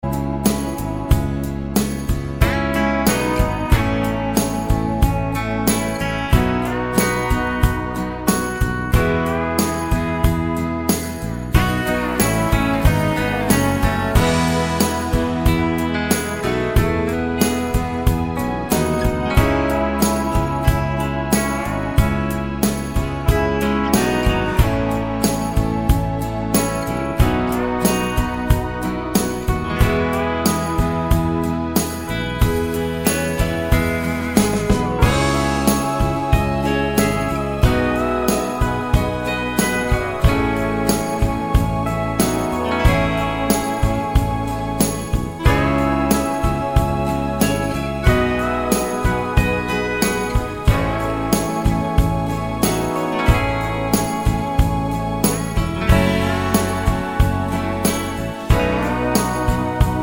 no Backing Vocals Soft Rock 4:38 Buy £1.50